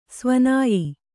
♪ svanāyi